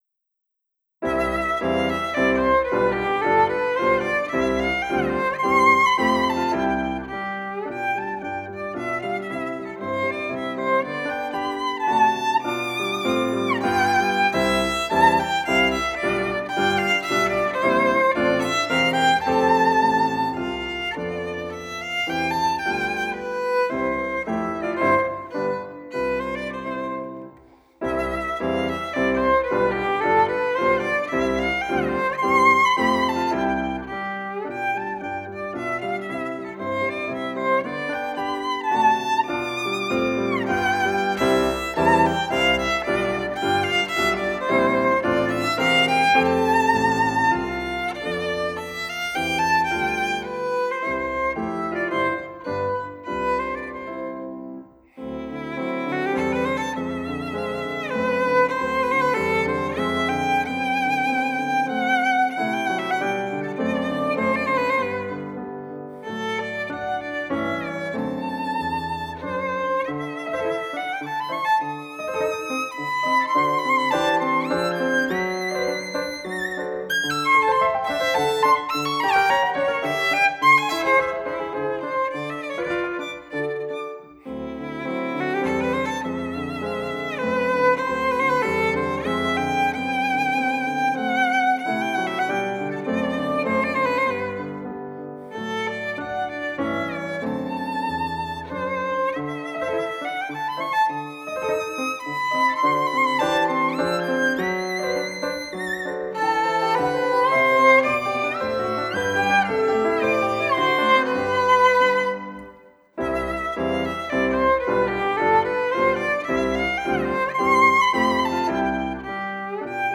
4 Sonata para violín y piano - III Minuetto.aiff (58.38 MB)